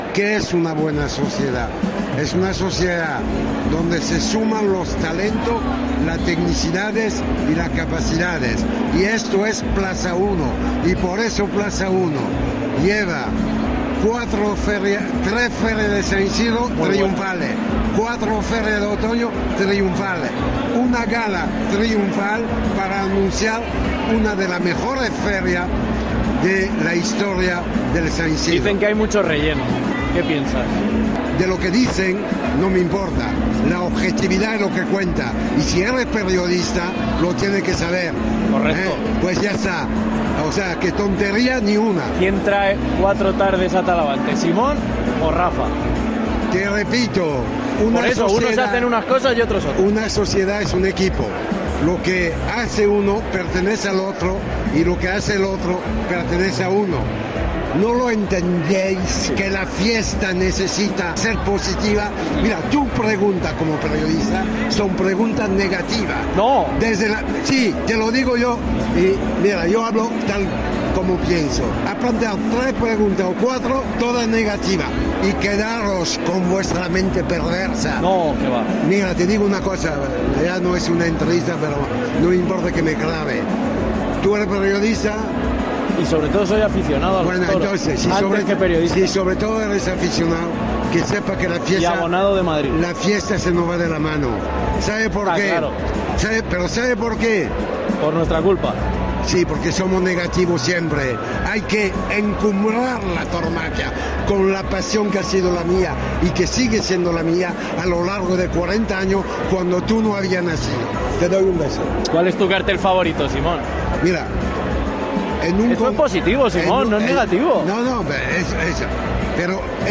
Fiel a su personalidad y con su estilo vehemente, Simón Casas fue otro de los protagonistas de la gala de presentación de carteles de la Feria de San Isidro.